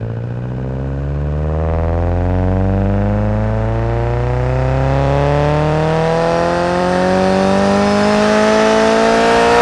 rr3-assets/files/.depot/audio/Vehicles/i4_06/i4_06_accel.wav
i4_06_accel.wav